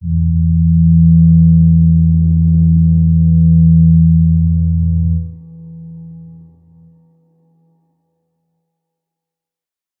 G_Crystal-E3-pp.wav